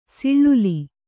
♪ siḷḷuli